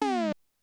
menu_open.wav